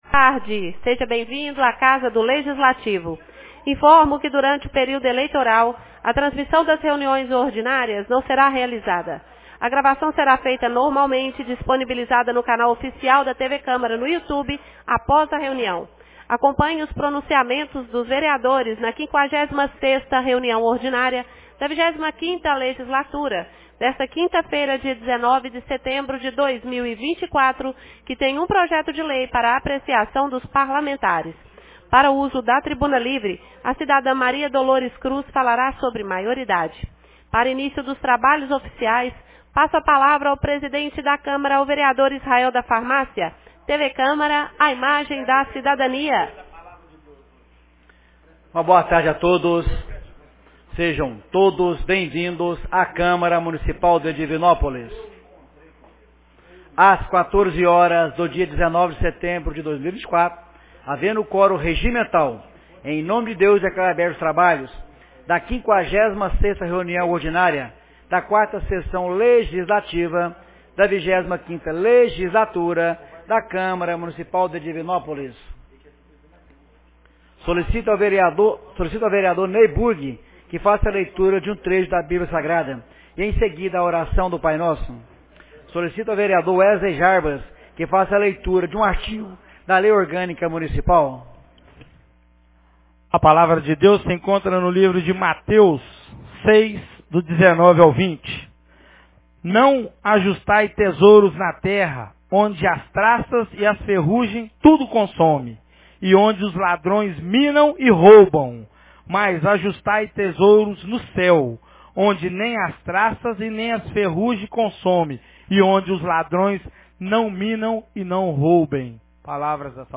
56ª Reunião Ordinária 19 de setembro de 2024